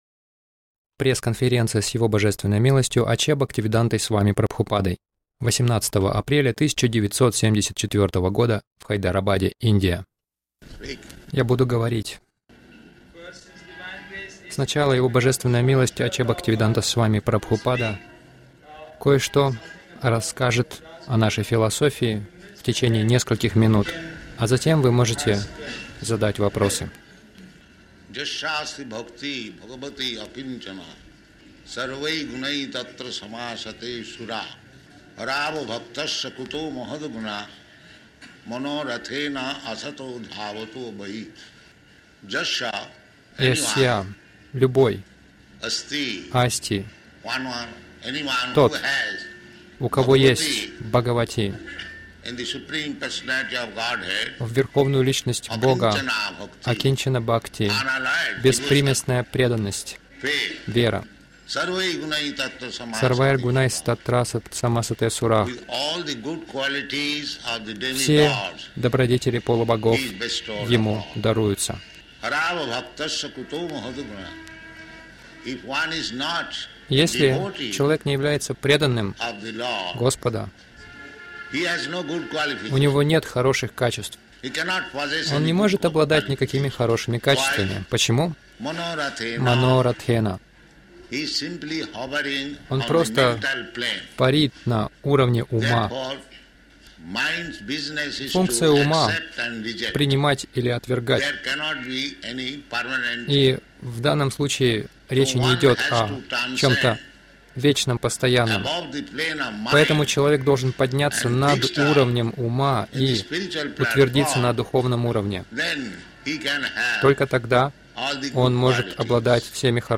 Пресс конференция — Правительства ведут людей не туда